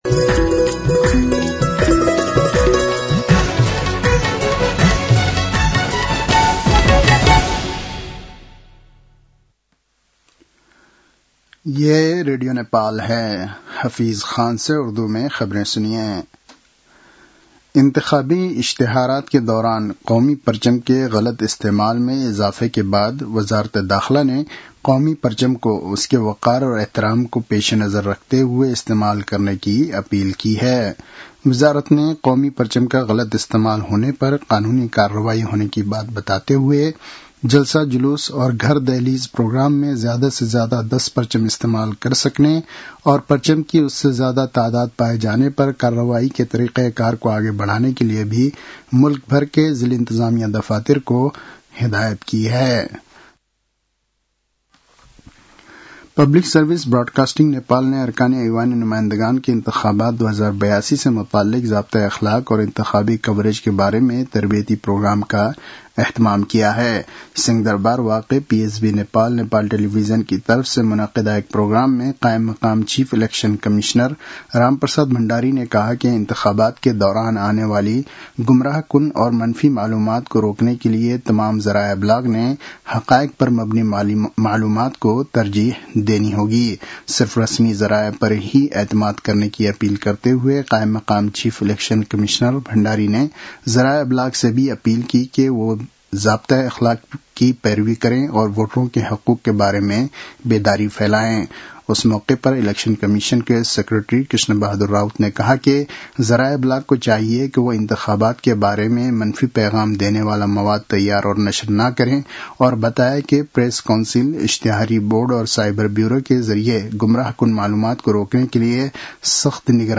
उर्दु भाषामा समाचार : १२ माघ , २०८२